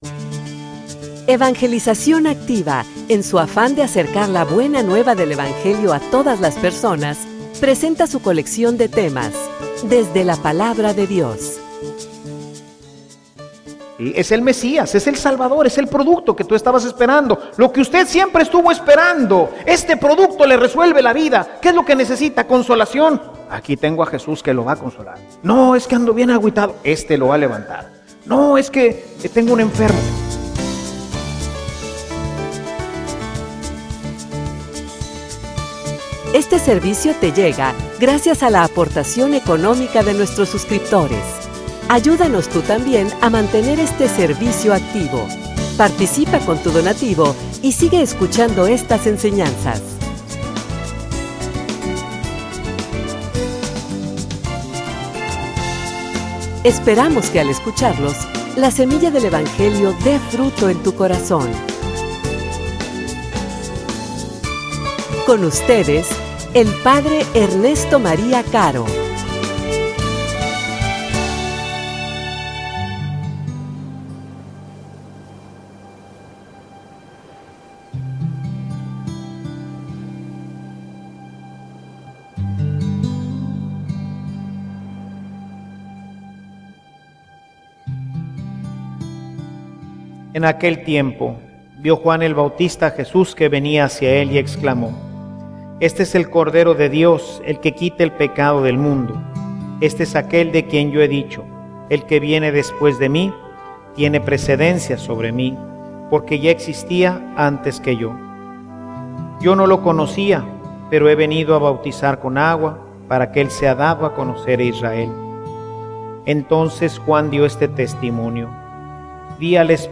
homilia_Se_buscan_vendedores.mp3